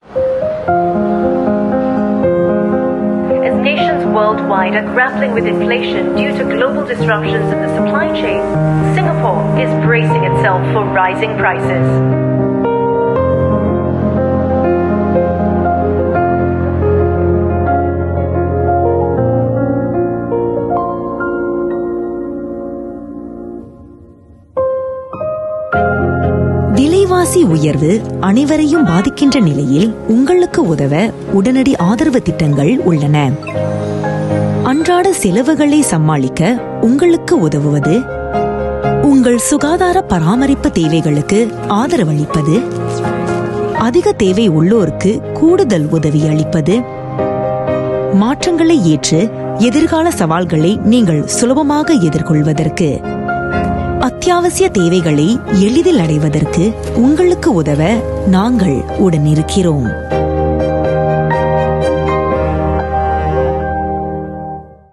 Voice Samples: Voice Sample 02
female